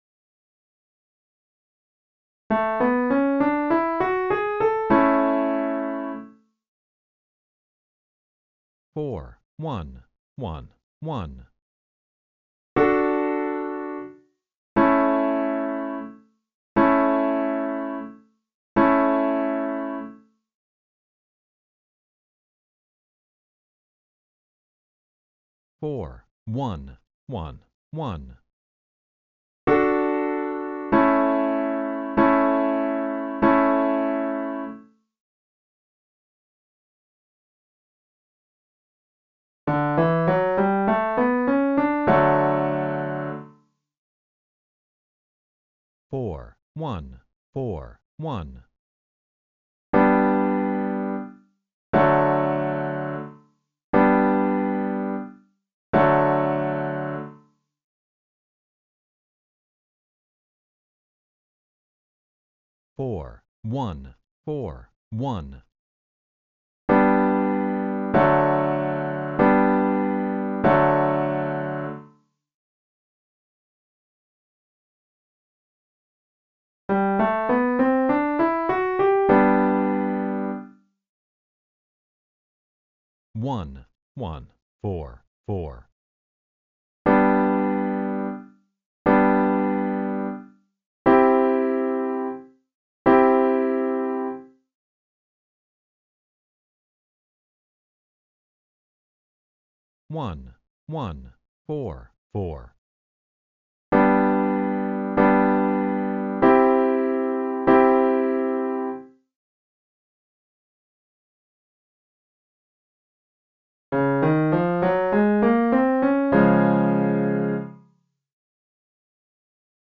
Training Exercise 1. I, IV (easy).mp3